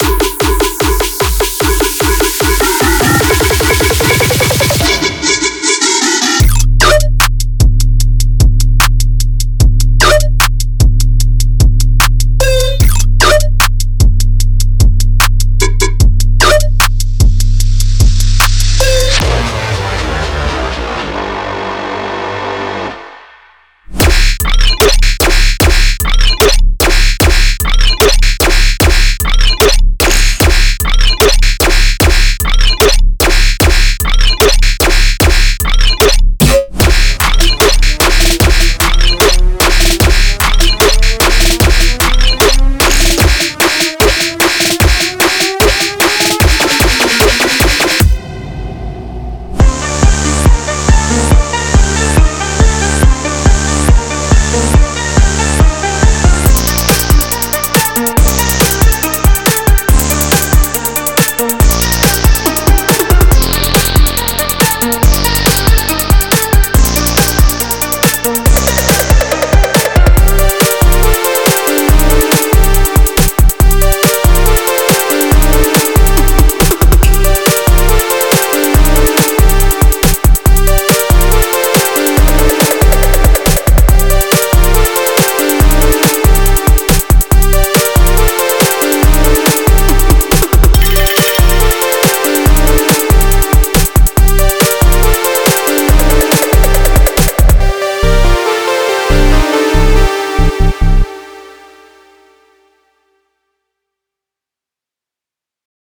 21 Loops
10 One Shots